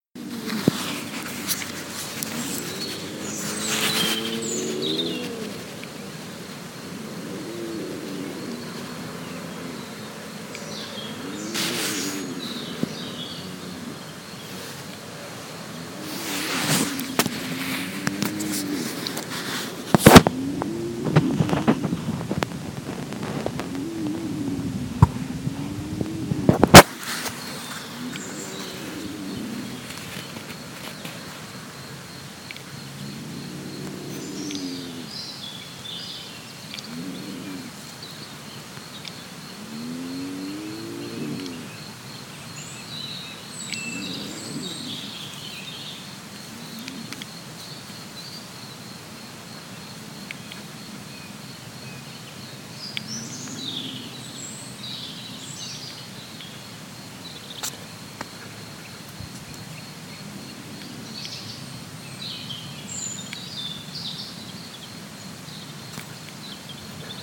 Jetzt höhre ich ein tiefe, drohendes Knurren. Der Luchs stösst die Laute, offensichtlich erregt, fortwährend aus und nähert sich noch mehr. Ganz ganz langsam ziehe ich mein IPhone aus der Tasche und statt zu fotografieren entschliesse ich mich, das Knurren aufzunehmen.